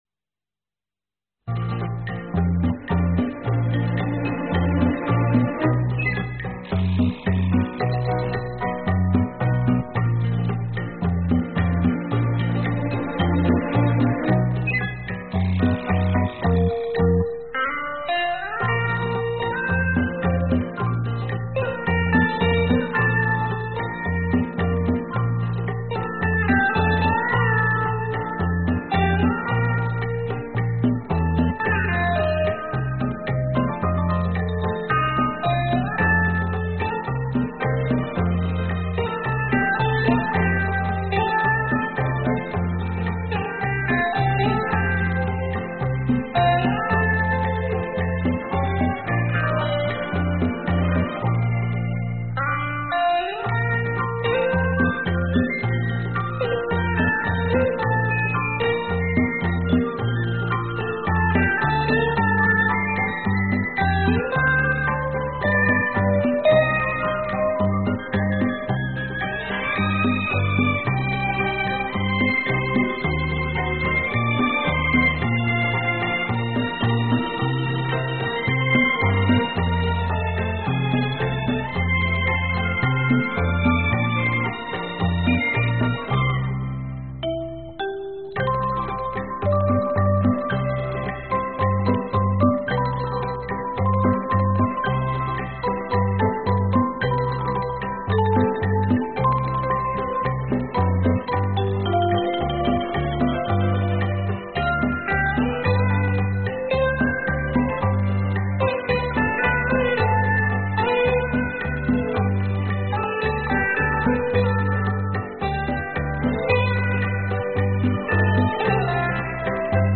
此专辑为探戈和恰恰节奏的舞曲，节奏欢快，听起来很轻松，适合郊外Party、跳舞或者开车闲逛。